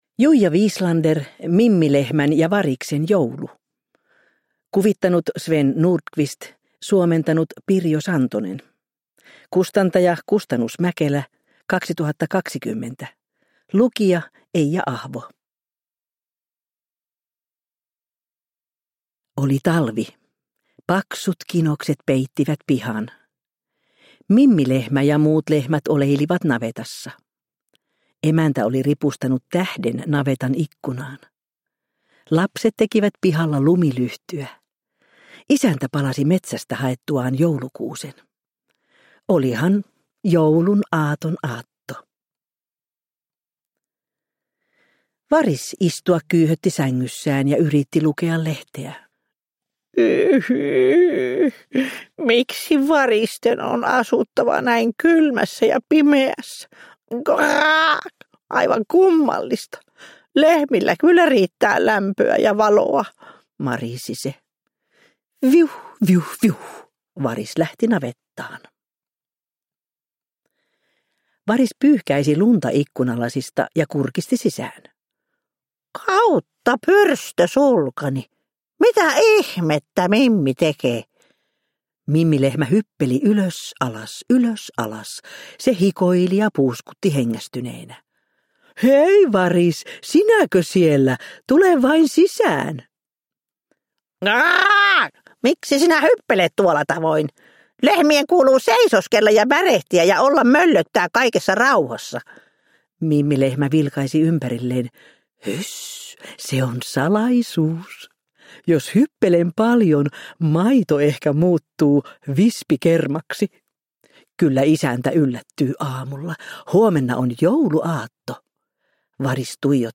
Mimmi Lehmän ja Variksen joulu – Ljudbok – Laddas ner